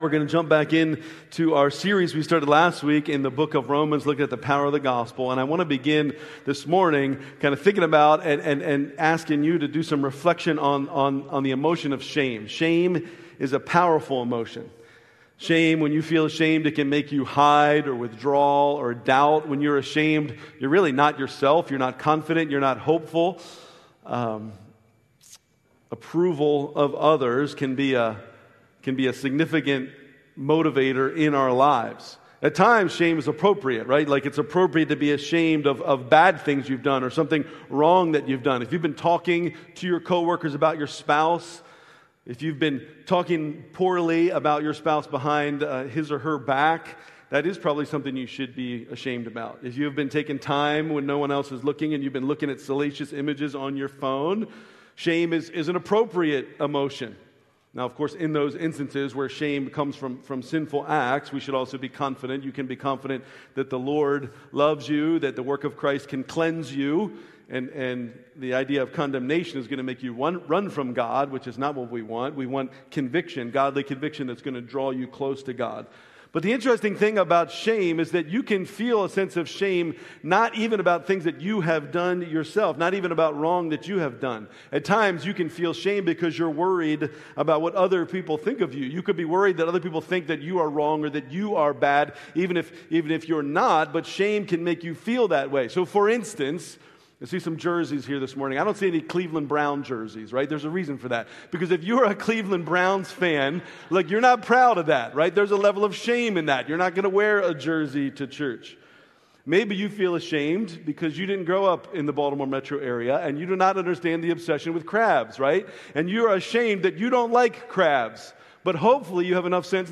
Living Hope Church Worship Service - September 7, 2025